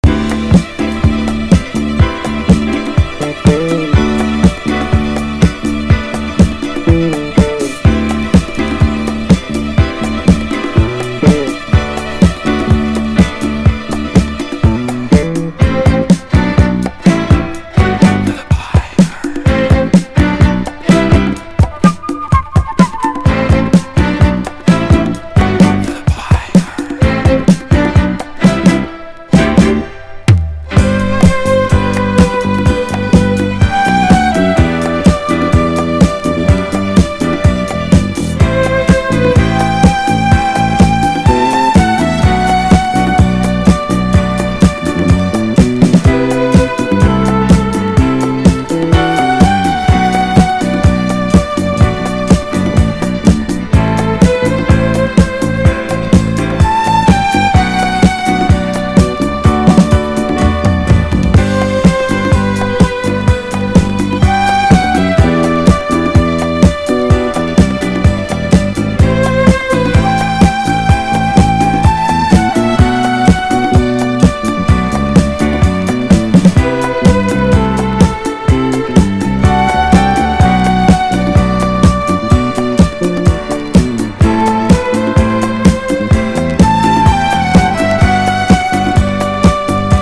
軽快なグルーヴの長尺ジャズ・ファンク～ディスコ
グルーヴィーなFUNKビートの上をフルートだけにとどまらず様々な楽器が演奏されるJAZZYな展開が最高!!